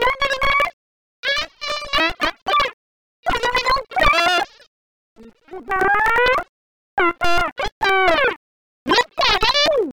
ＦＭ音源２音による音声なので音質は悪いです。
確認用に出力される音声